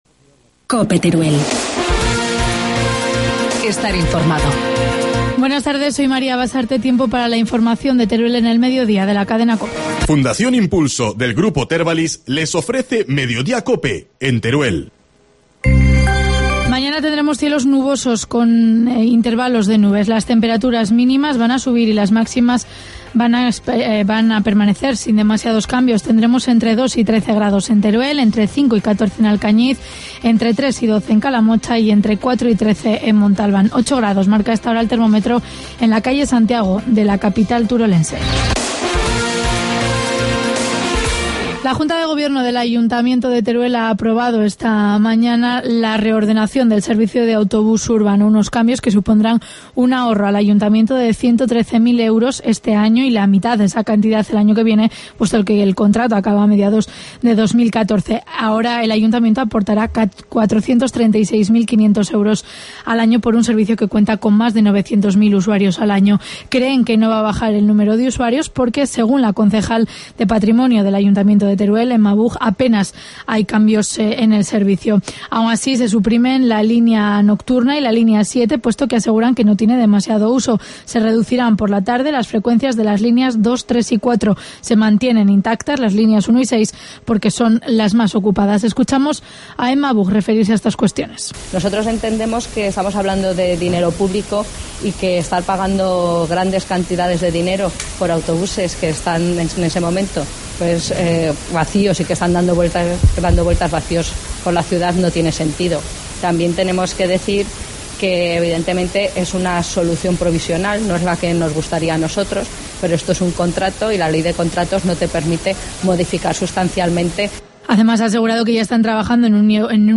Informativo medio día, lunes 4 de febrero